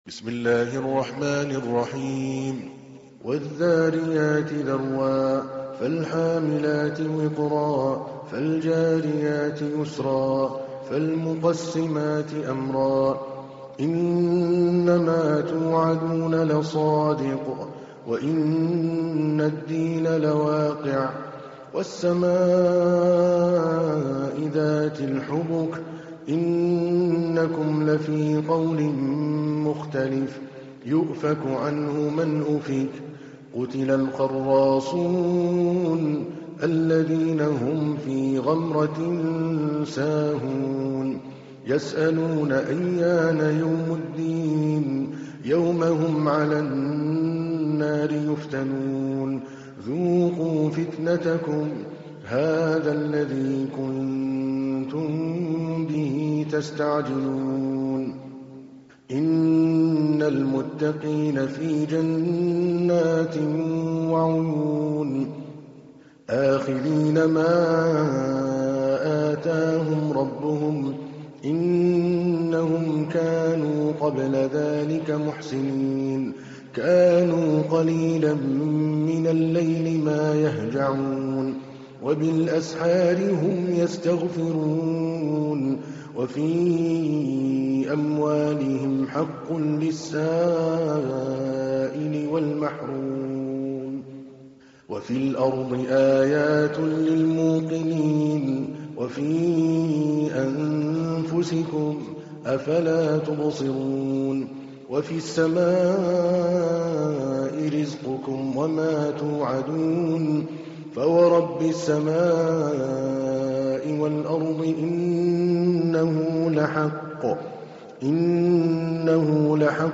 تحميل : 51. سورة الذاريات / القارئ عادل الكلباني / القرآن الكريم / موقع يا حسين